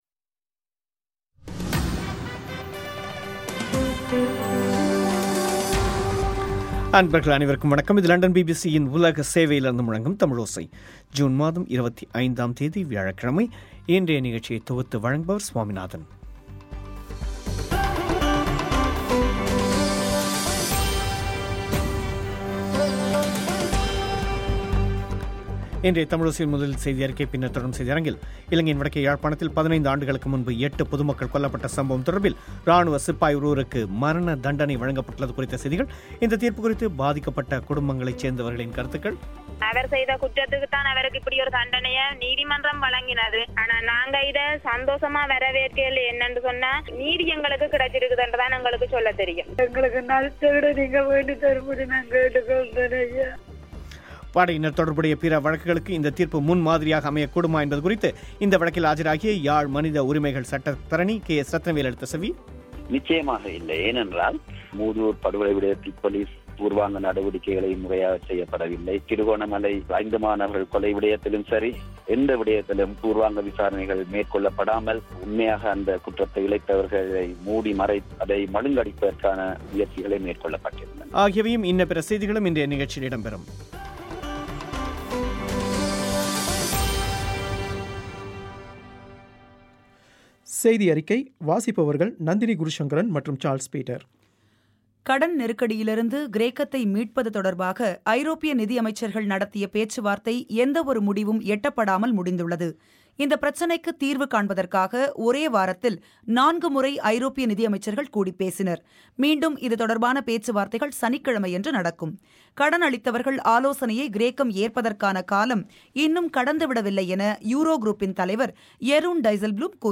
முக்கியச் செய்திகள்